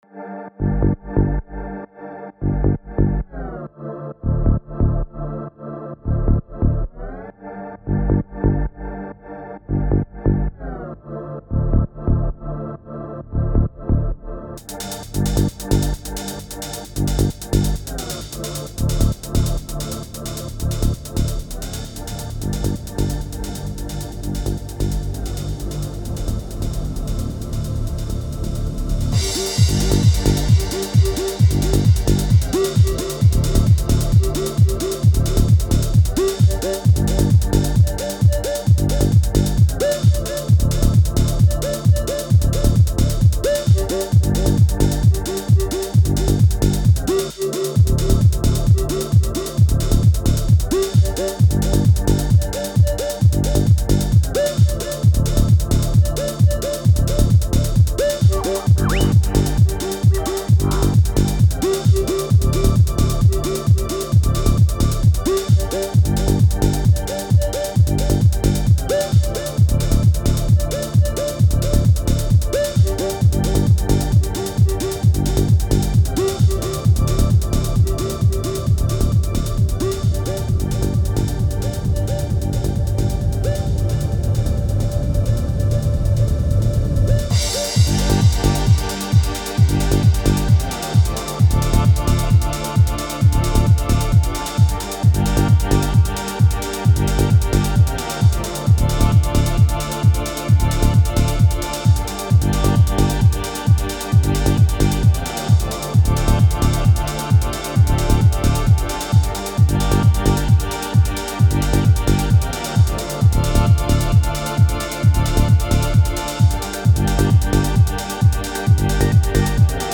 Relaxing
Scary
Upbeat